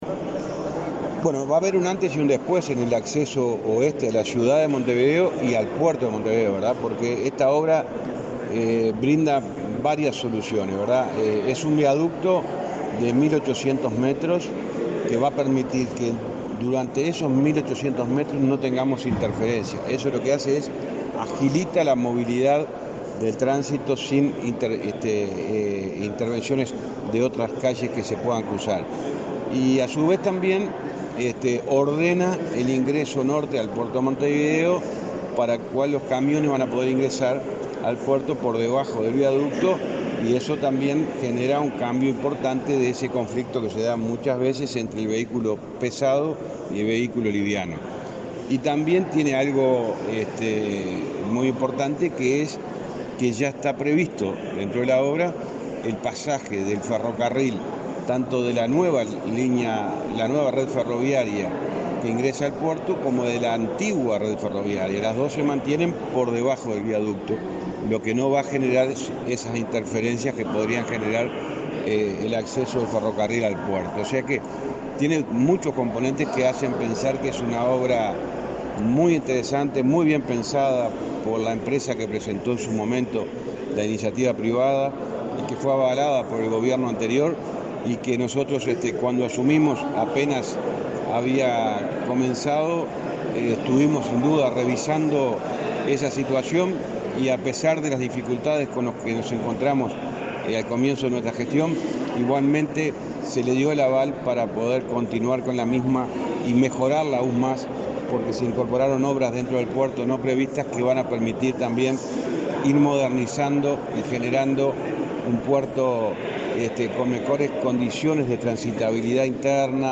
Entrevista al ministro de Transporte, José Luis Falero